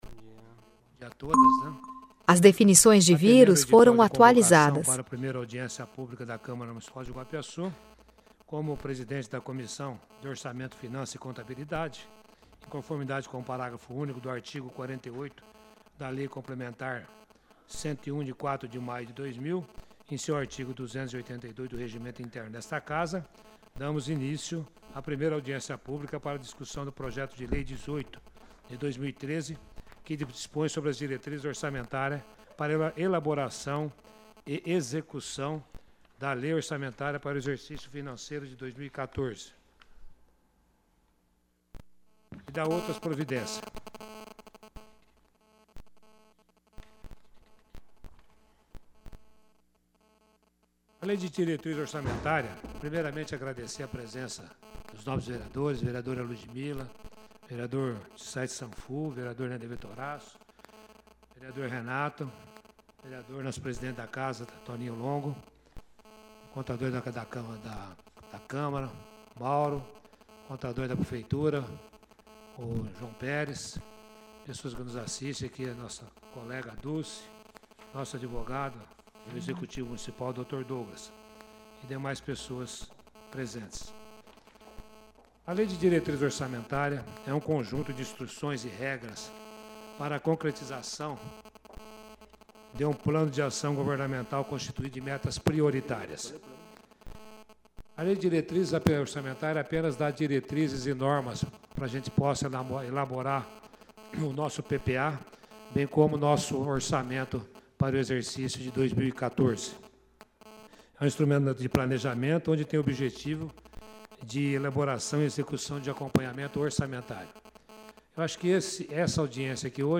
Áudio da Audiência Pública